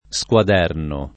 squaderno [ S k U ad $ rno ]